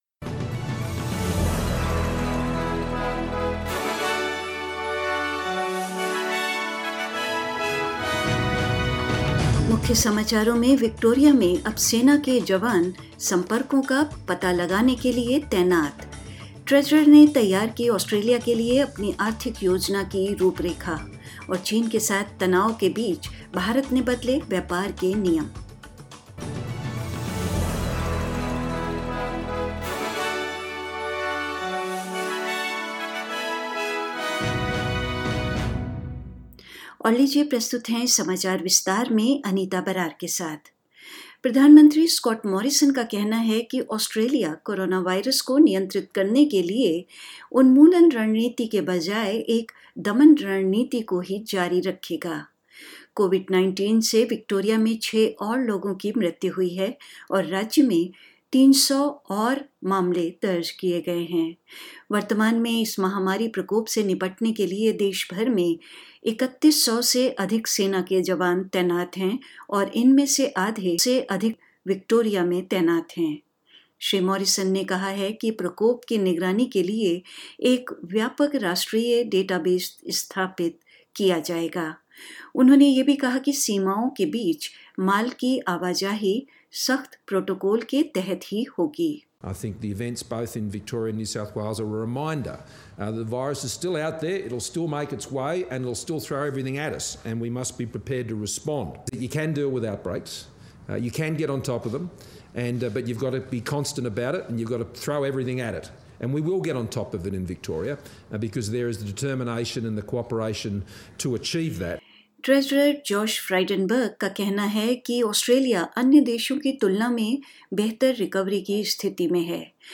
Hindi News 24th July 2020